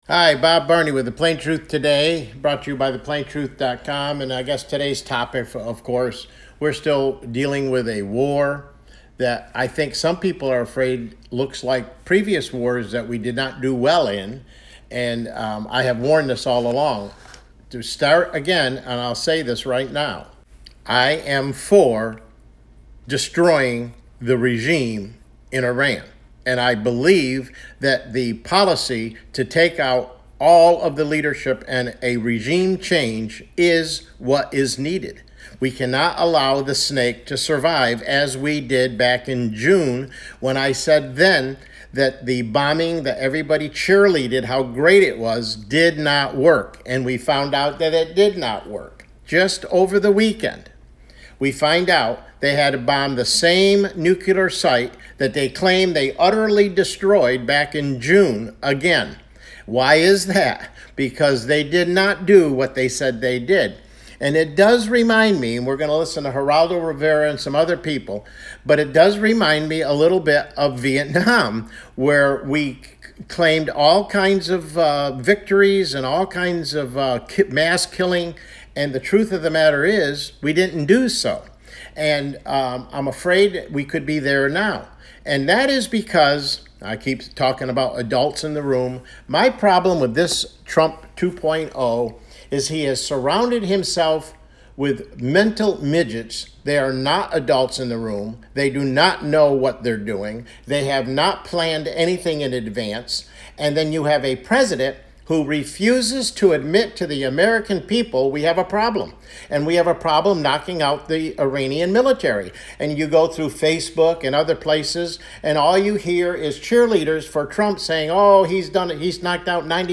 CLICK HERE TO LISTEN TO THE PLAIN TRUTH TODAY MIDDAY BROADCAST: Are We Losing the War with Iran? It Appears So!